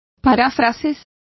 Complete with pronunciation of the translation of paraphrases.